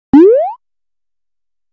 Hiệu ứng âm thanh Nhảy Qua, Bước Qua